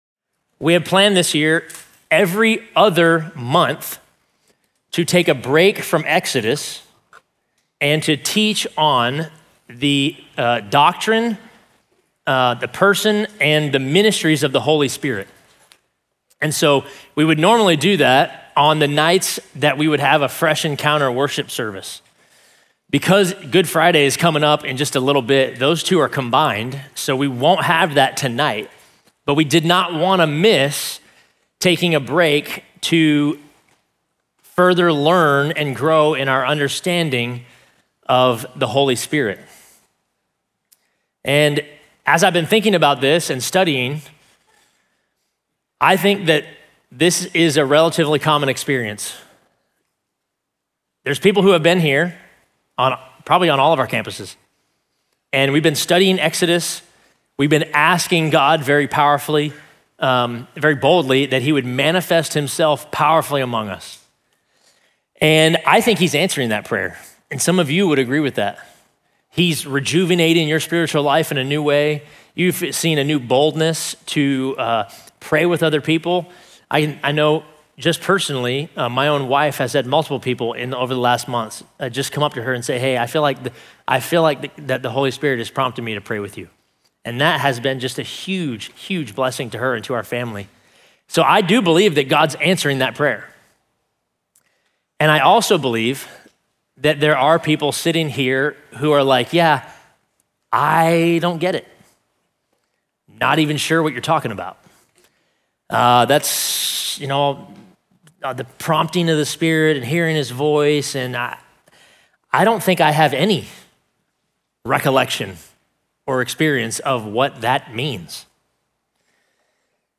Listen to the second of our 2026 Fresh Encounter sermons